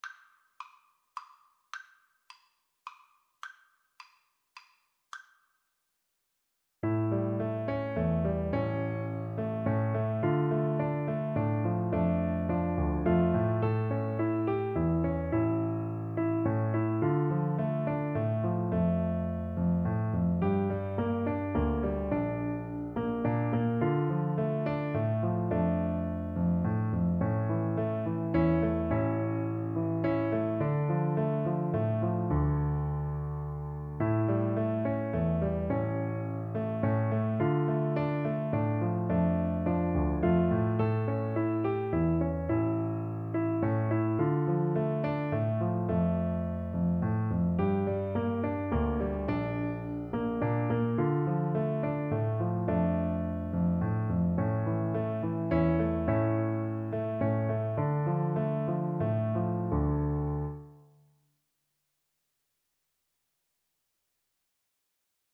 Slow Waltz = c. 106
3/4 (View more 3/4 Music)